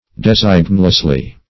designlessly - definition of designlessly - synonyms, pronunciation, spelling from Free Dictionary
-- De*sign"less*ly , adv.